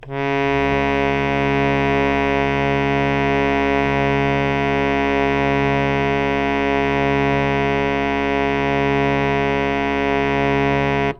harmonium
Cs2.wav